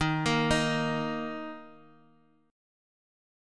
D#5 chord